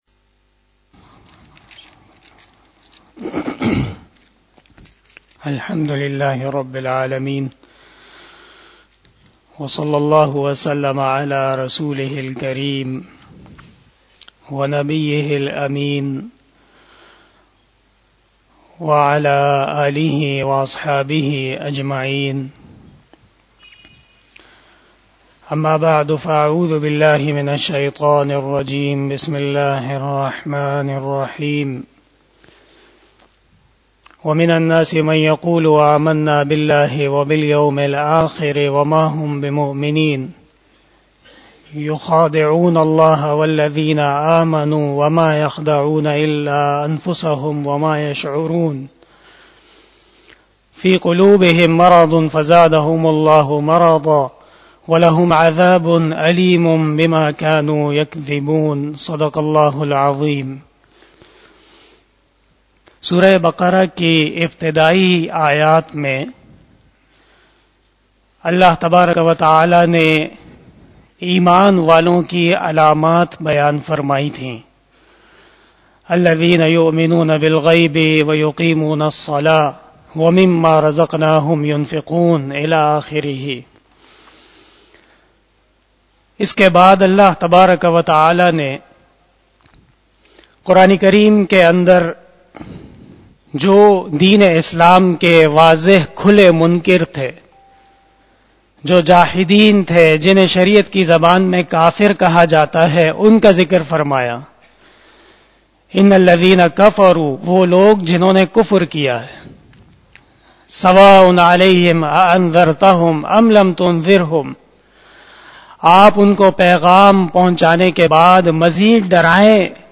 بیان شب جمعۃ المبارک